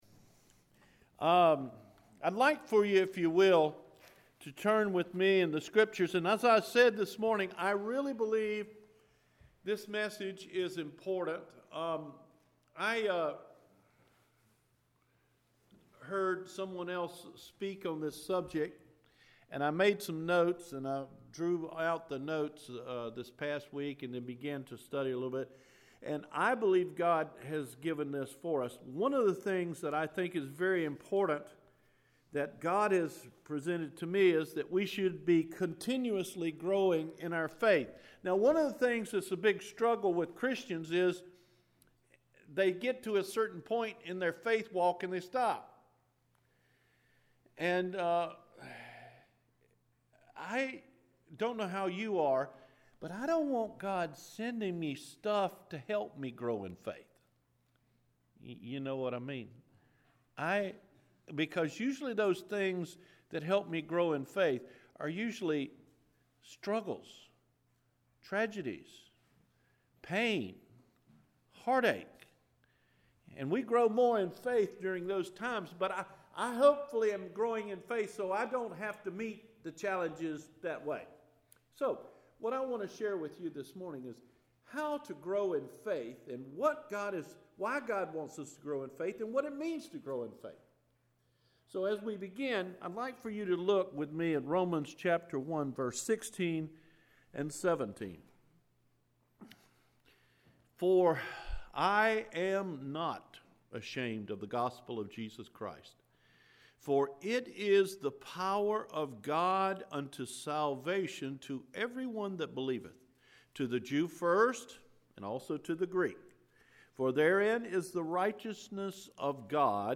How to Grow In Faith – January 8 2018 Sermon